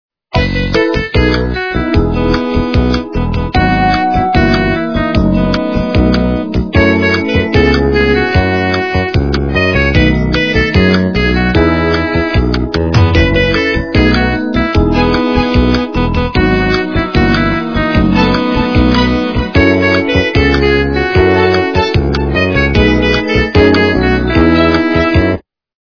- русская эстрада
качество понижено и присутствуют гудки.